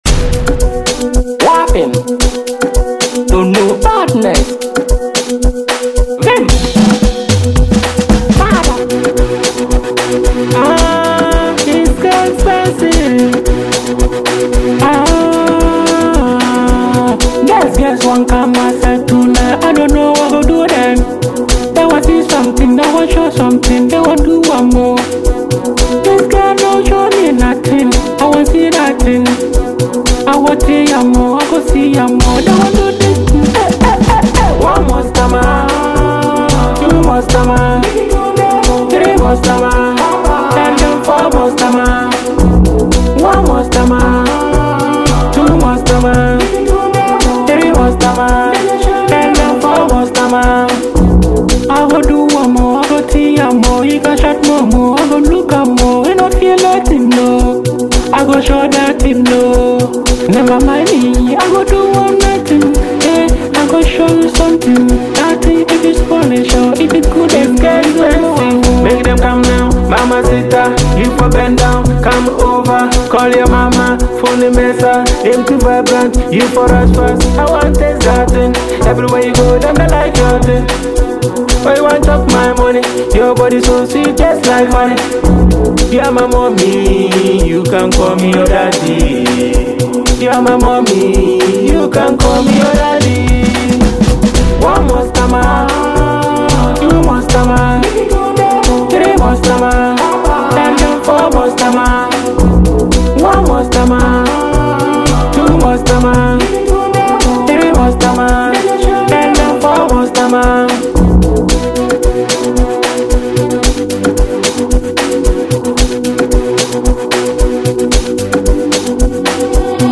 A Sultry Track